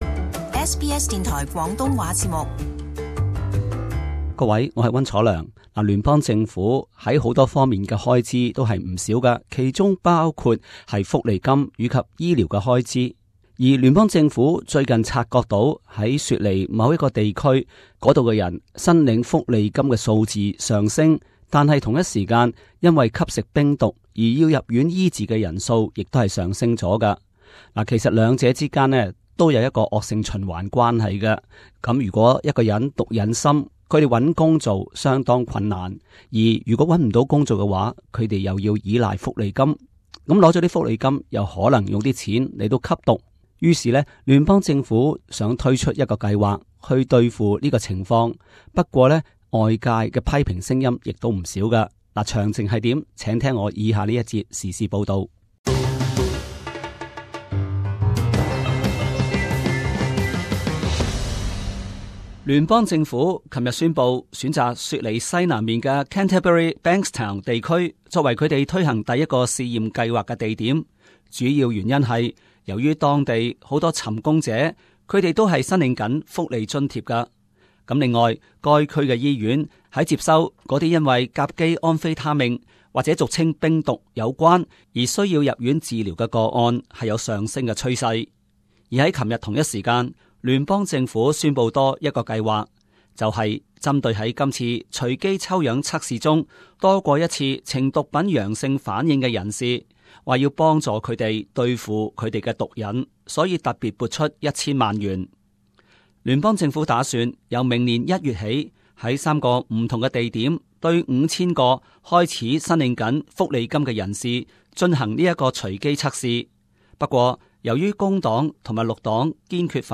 【时事报导】 政府随机检验吸毒福利金申领者遭批评